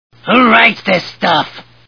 The Simpsons [Krusty] Cartoon TV Show Sound Bites